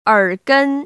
[ěrgēn] 얼껀  ▶